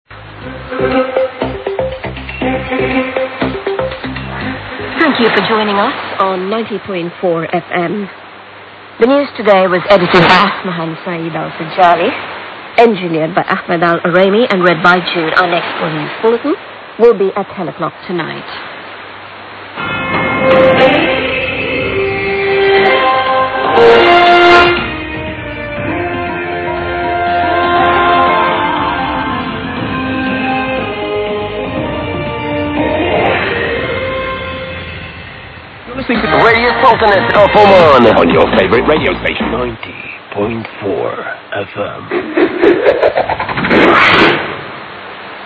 Radio Oman 1975, 1985, 1989, 2015 Station ID and Program Audio